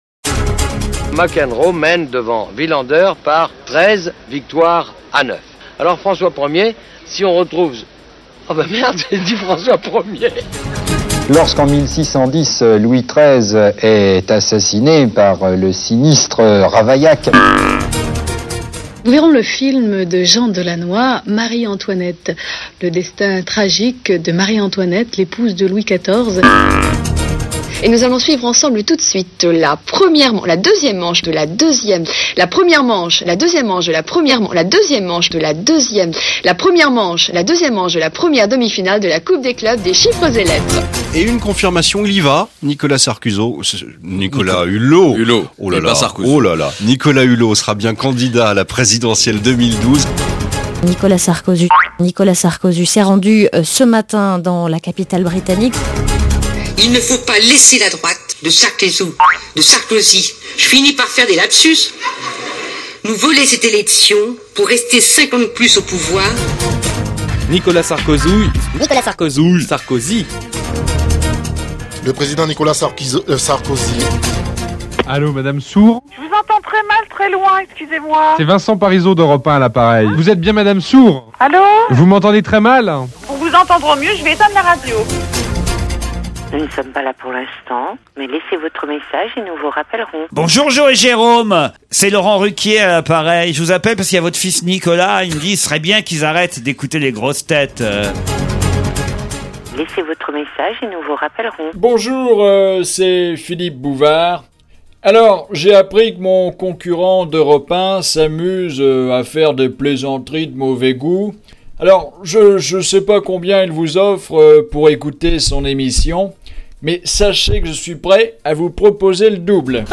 Anachronismes, bafouillages, fautes de français, patronymes estropiés, gaffes, incidents de plateau, et une ou deux surprises à découvrir dans ce 52ème bêtisier d'OVSG...